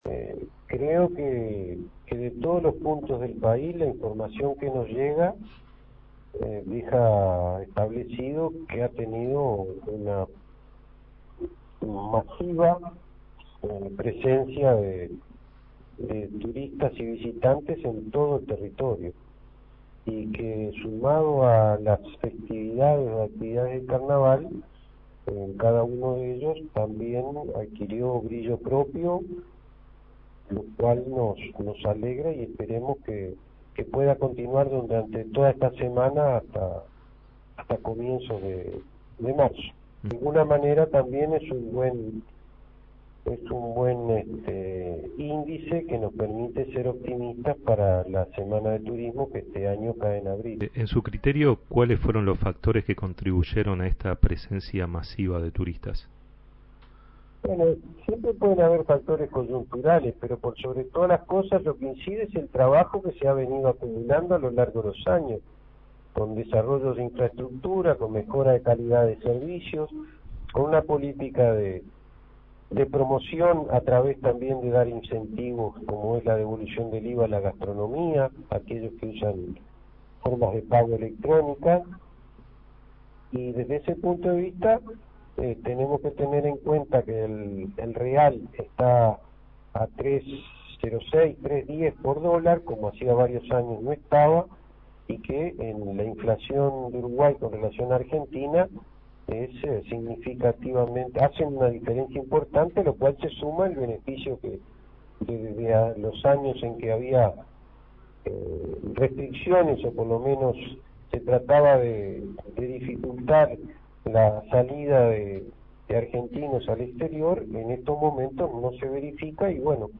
El desarrollo de infraestructura, la mejora en la calidad de los servicios y una política de promoción con incentivos de devolución de IVA a servicios turísticos abonados a través de medios electrónicos, fueron algunos factores que contribuyeron con la masiva presencia de turistas en el feriado de Carnaval, subrayó el subsecretario del Ministerio de Turismo, Benjamín Liberoff, en diálogo con la Secretaría de Comunicación.